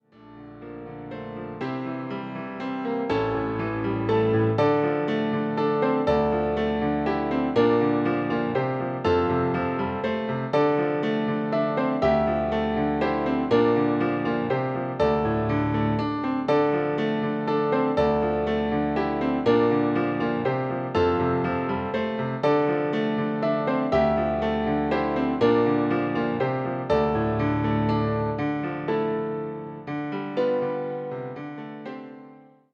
ピアノの入力
今回の曲は、１番ではベースを入れず２番から使いますので、ピアノが曲全体を引っ張っていくような感じの伴奏にする方向で行きます。
luflen-piano-2.mp3